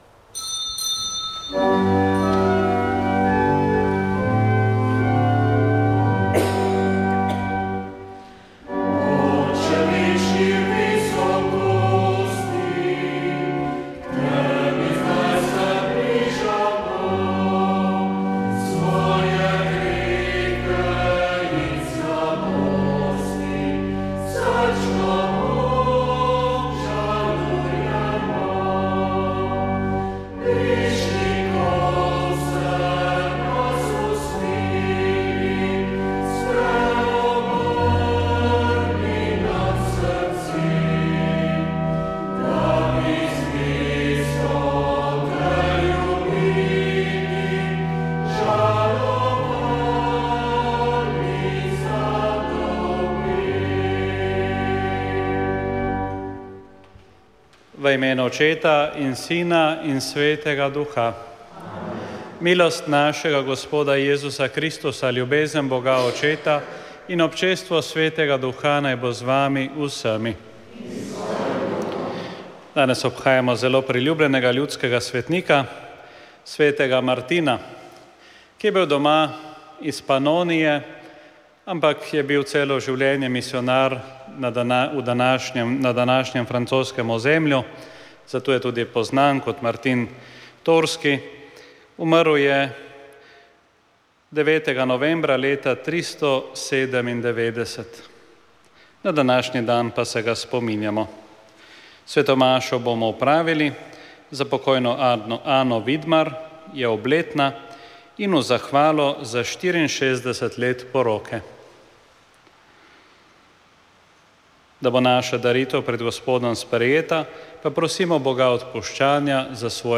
Sveta maša
Sveta maša za nadškofa Urana iz stolnice svetega Nikolaja v Ljubljani
Ob četrti obletnici smrti nadškofa Alojza Urana smo posneli sveto mašo, ki jo je daroval njegov naslednik na škofovskem sedežu nadškof Stanislav Zore.